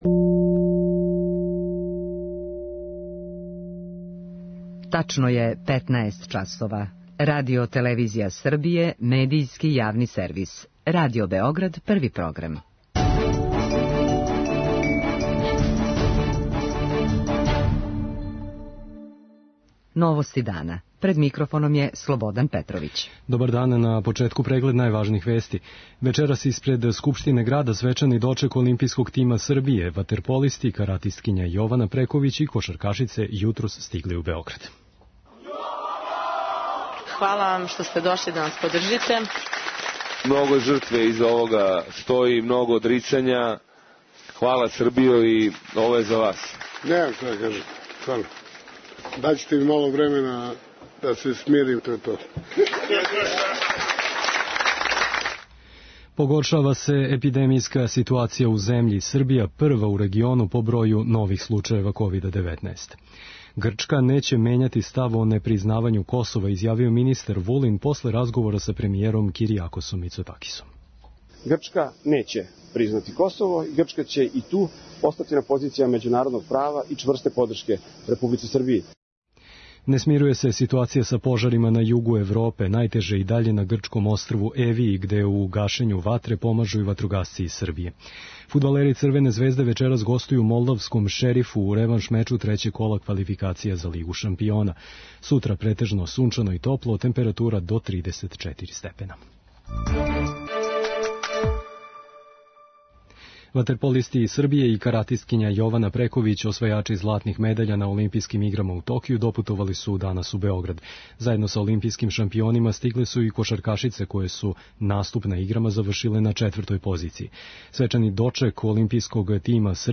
Освајаче медаља и чланове њихових стручних штабова вечерас ће примити и председник Александар Вучић. преузми : 6.14 MB Новости дана Autor: Радио Београд 1 “Новости дана”, централна информативна емисија Првог програма Радио Београда емитује се од јесени 1958. године.